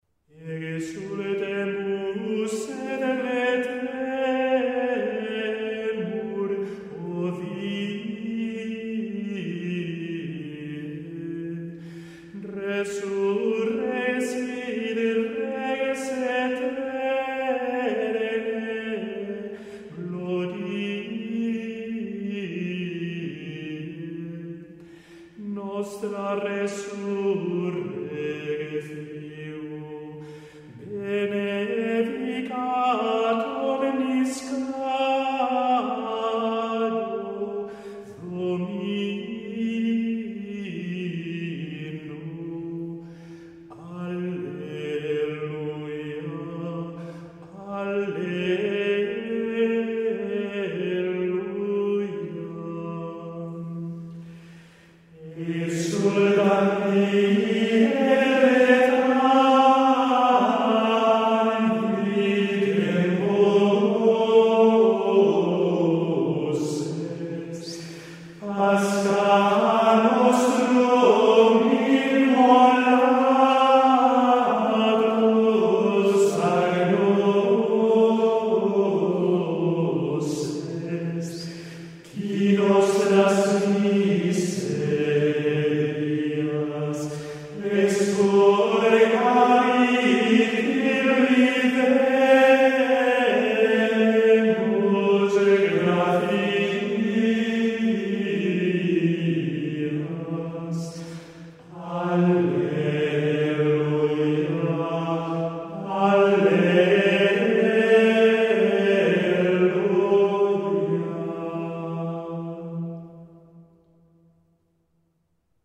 Exultemus et letemur. Tropo de Benedicamus Domino, Modo III  (1’56»)